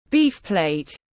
Thịt ba chỉ bò tiếng anh gọi là beef plate, phiên âm tiếng anh đọc là /biːf pleɪt/
Beef plate /biːf pleɪt/